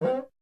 Play Cuica Sound Note 1 - SoundBoardGuy
cuica-sound-note-1.mp3